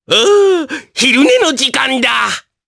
Oddy-Vox_Victory_jp.wav